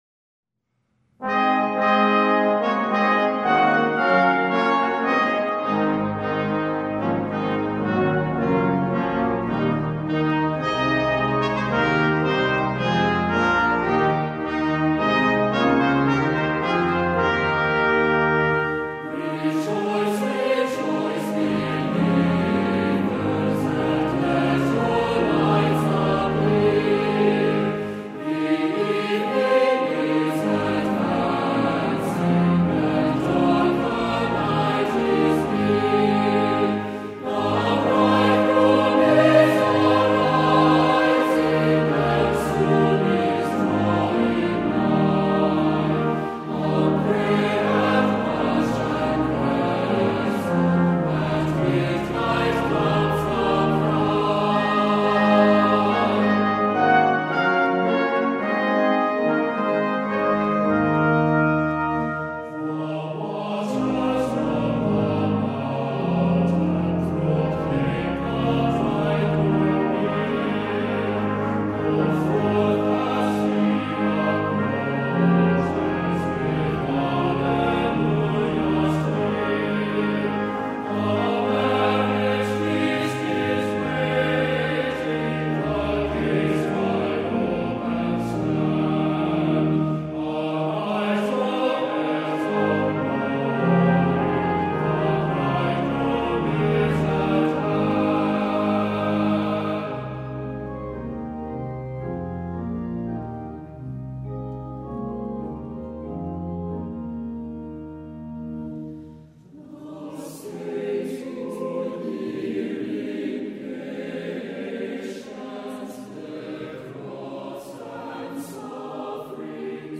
Voicing: SATB, Optional Congregation